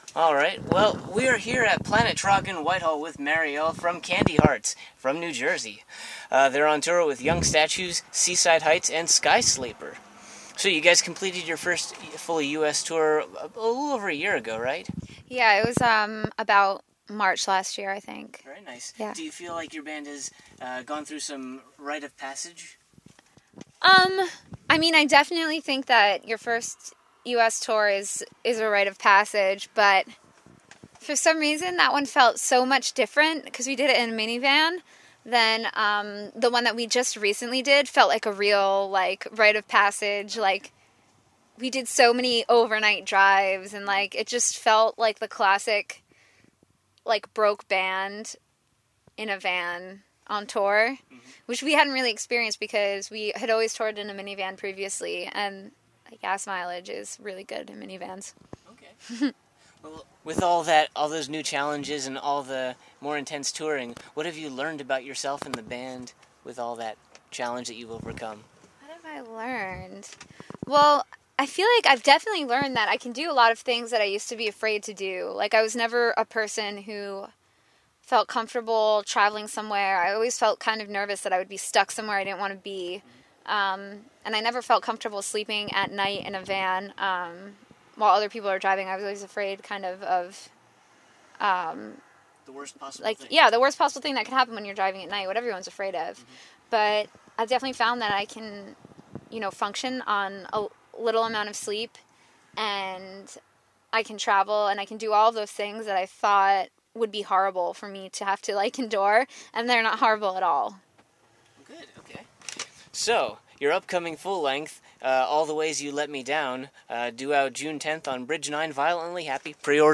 Exclusive: Candy Hearts Interview
45-interview-candy-hearts.mp3